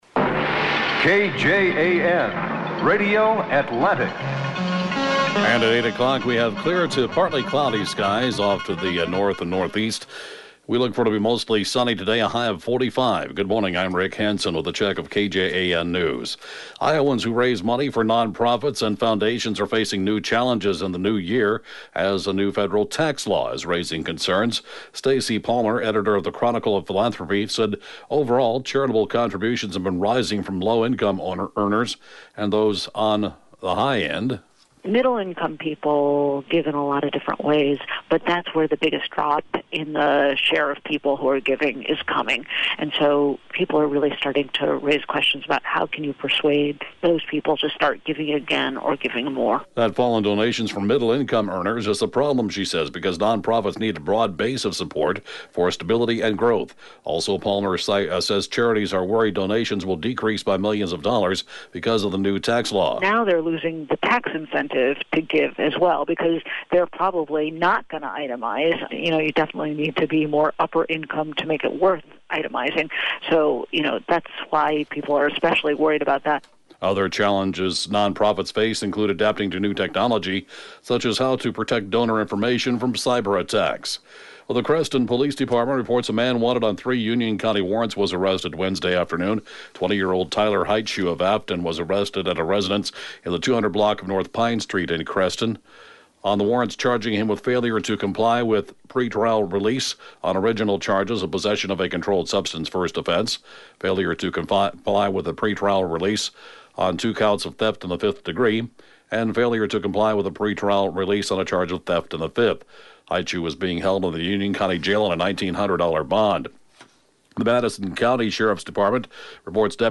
(Podcast) KJAN Morning News & Funeral report, 2/9/2019